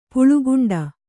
♪ puḷuguṇḍu